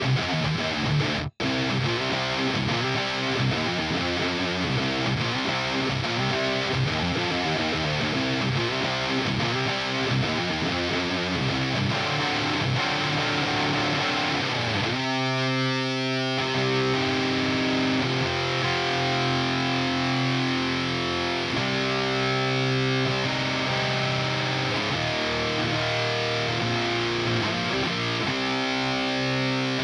Das Fuzz-Pedal ist eigentlich auch toll. Klingt schön kaputt: Anhang anzeigen 127365